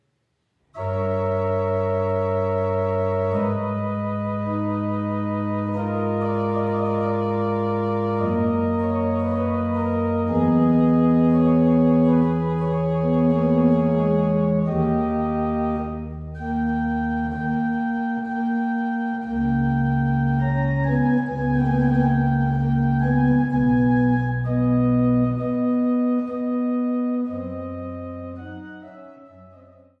an der historischen Orgel zu Niederndodeleben
Orgel